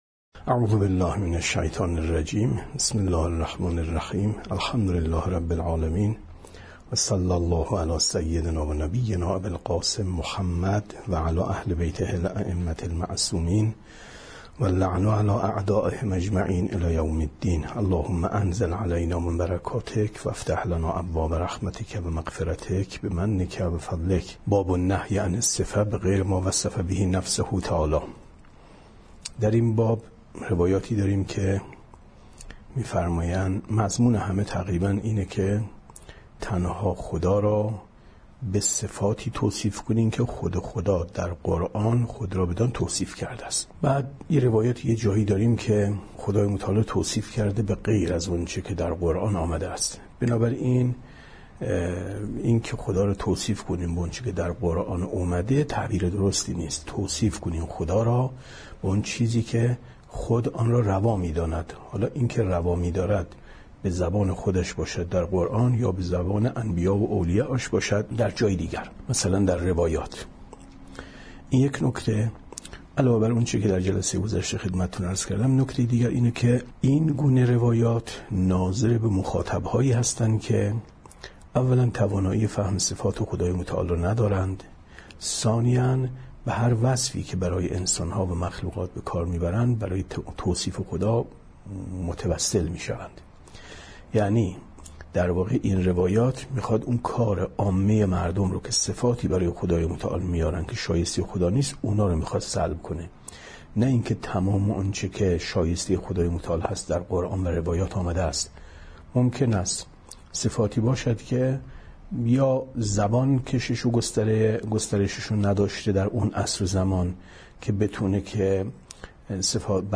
کتاب توحید ـ درس 55 ـ 26/ 10/ 95